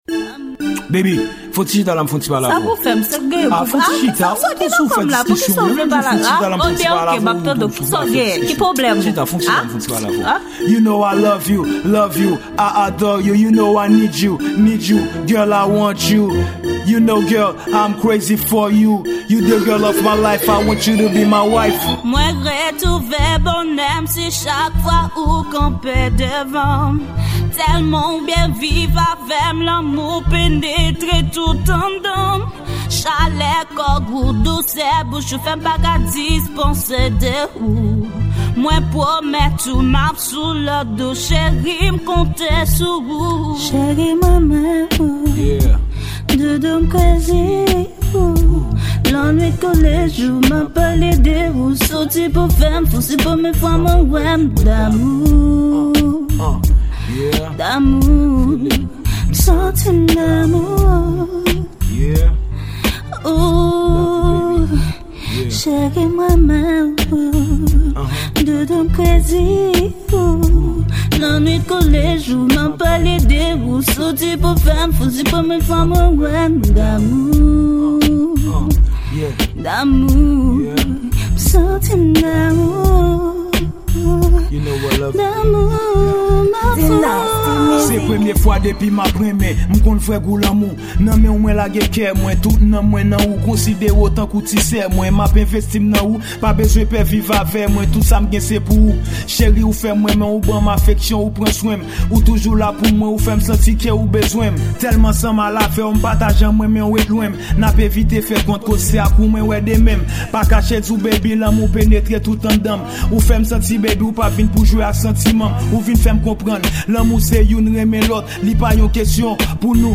Genre:Rap.